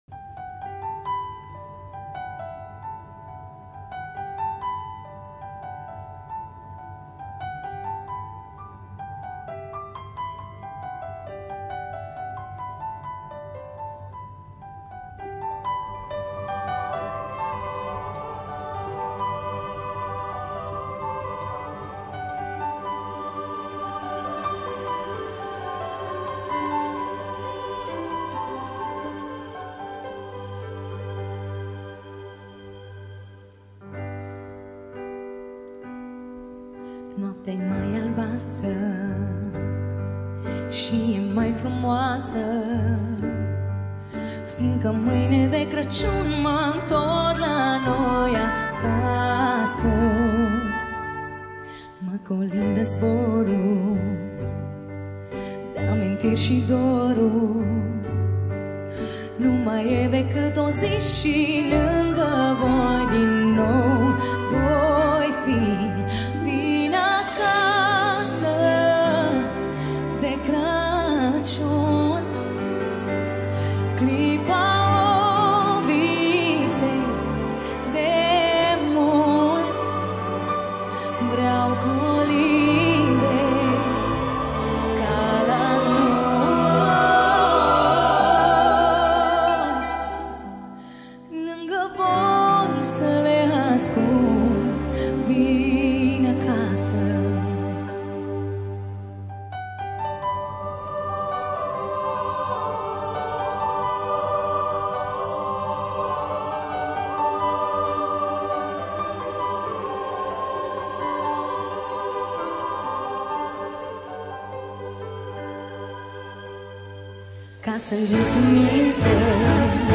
live La Opera Nationala
Data: 12.10.2024  Colinde Craciun Hits: 0